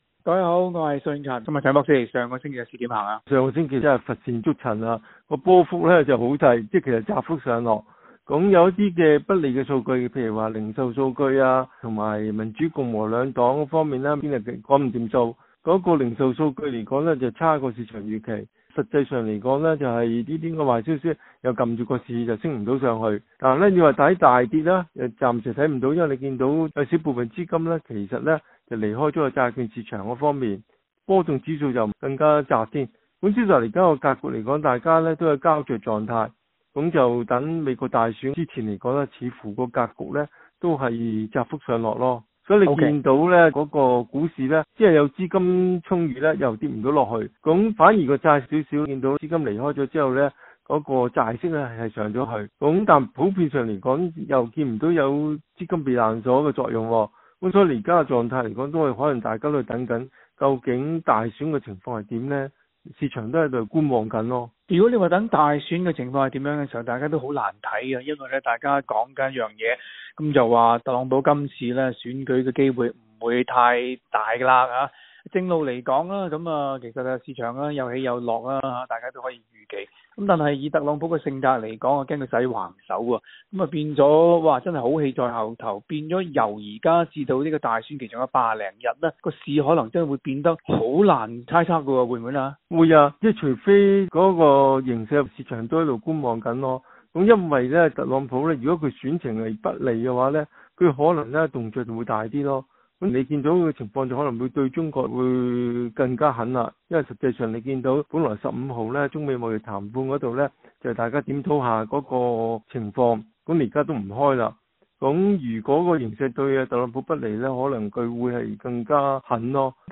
cantonese_gfmon_1708.mp3